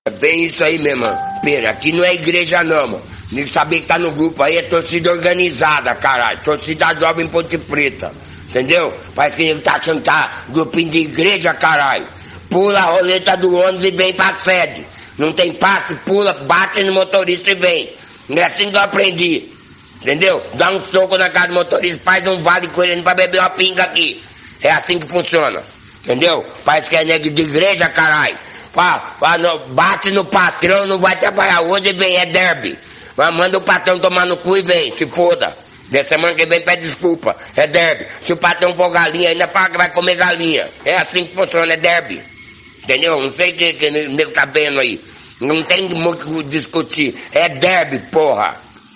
jacare e derbi Meme Sound Effect
Category: Sports Soundboard